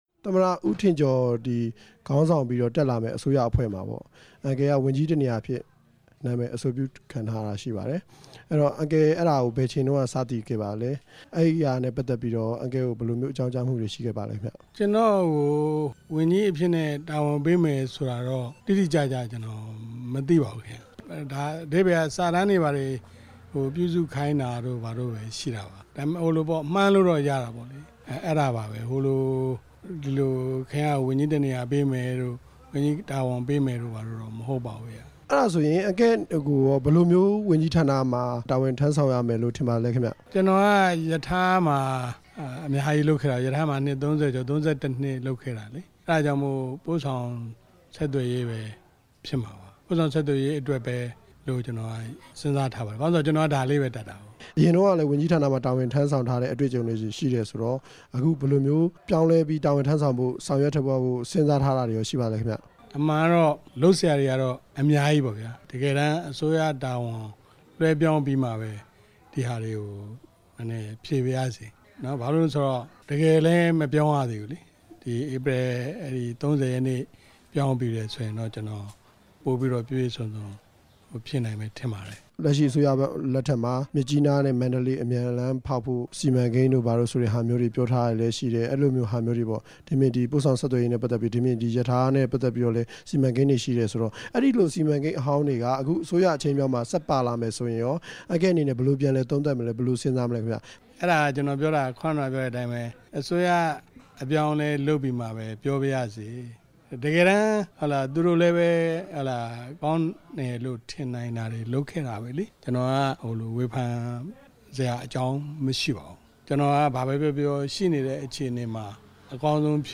ဦးသန့်စင်မောင်နဲ့ မေးမြန်းချက်